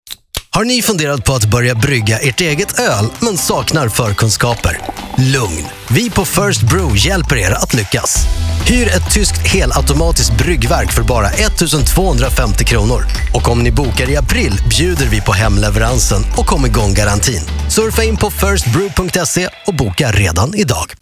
Lyssna på vår radio reklam